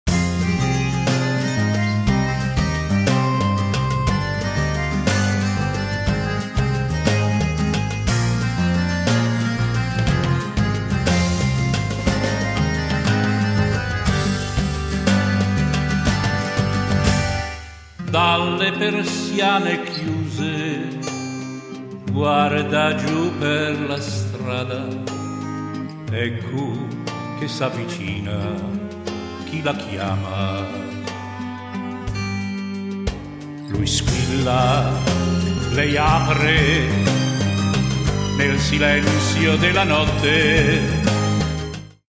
Voce calda e avvolgente